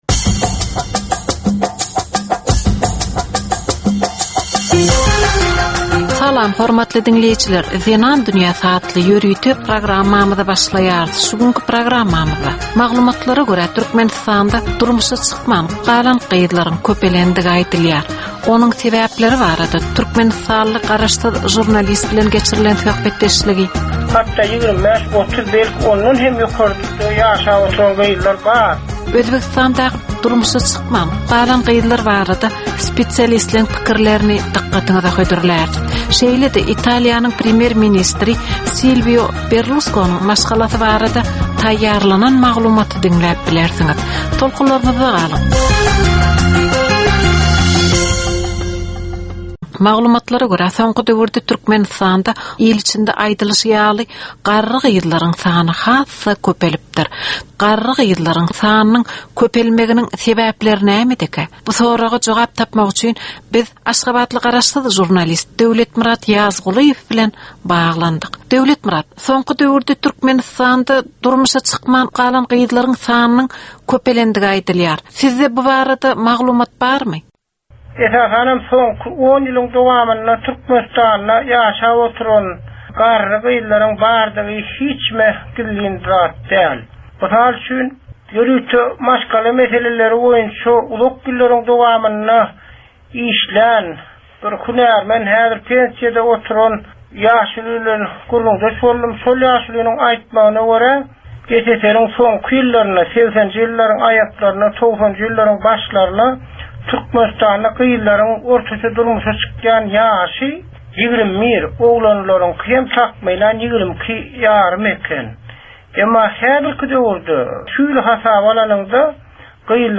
Türkmen we halkara aýal-gyzlarynyň durmuşyna degişli derwaýys meselelere we täzeliklere bagyşlanylyp taýýarlanylýan 15 minutlyk ýörite gepleşik. Bu gepleşikde aýal-gyzlaryn durmuşyna degişli maglumatlar, synlar, bu meseleler boýunça synçylaryň we bilermenleriň pikirleri, teklipleri we diskussiýalary berilýär.